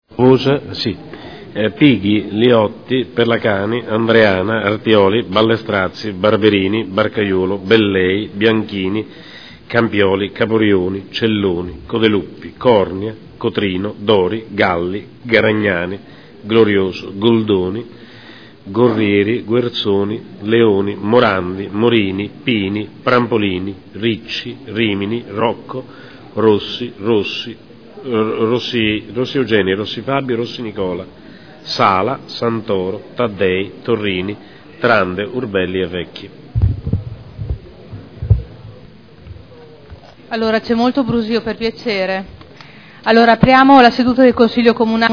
Seduta del 18/04/2011. Appello.